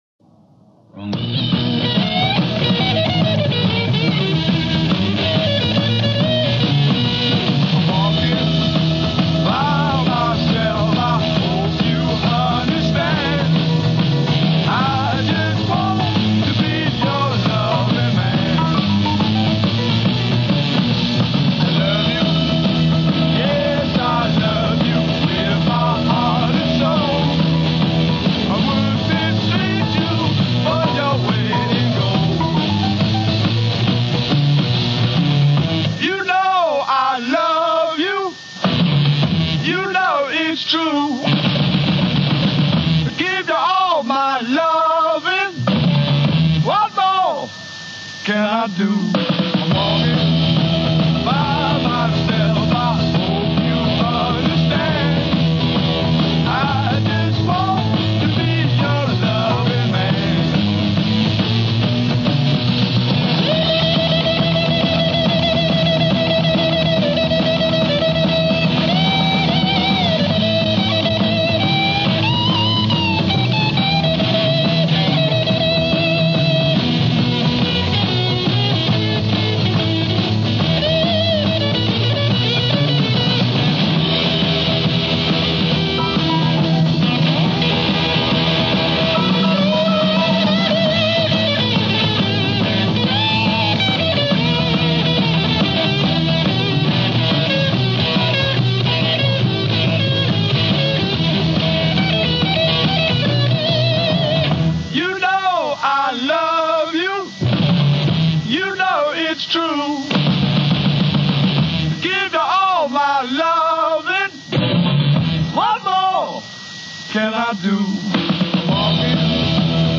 part of the British Blues Explosion of the 60s .